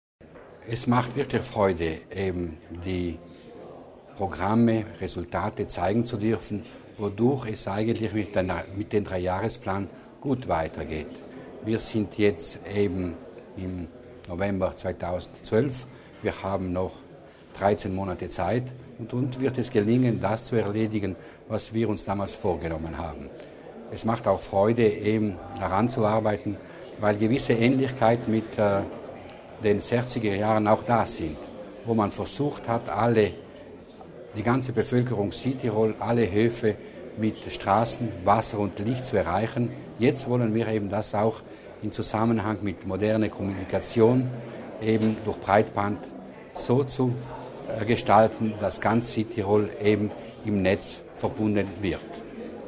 Landeshauptmann Durnwalder über die Bedeutung des schnellen Internet